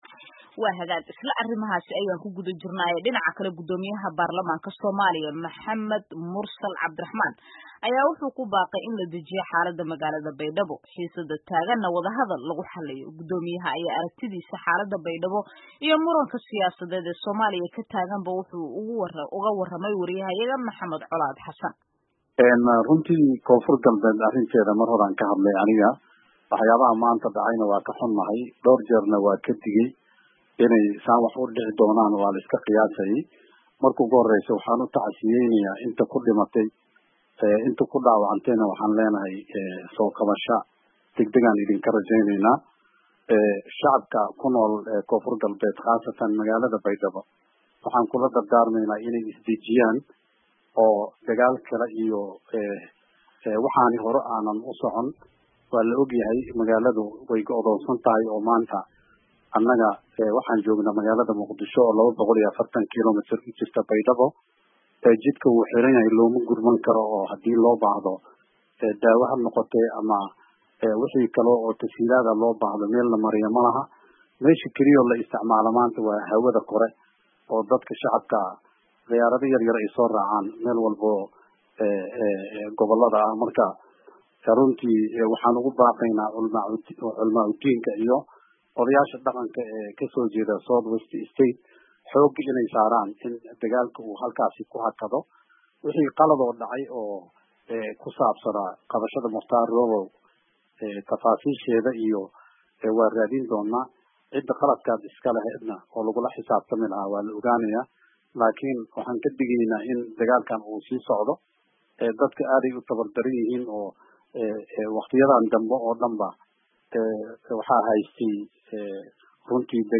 Wareysi xasaasi ah: Mursal oo mooshinka Farmaajo iyo xariga Roobow ka hadlay
Muqdisho (Caasimada Online) – Guddoomiyaha baarlamanka Soomaaliya, Maxamed Mursal Cabdiraxmaan ayaa wareysi xasaasi ah siiyey idaacadda VOA, kaasi oo ah kiisii ugu horreeyey tan iyo markii uu aqbalay mooshinka xil ka qaadista madaxweyne Maxamed Cabdullaahi Farmajao.